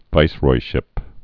(vīsroi-shĭp)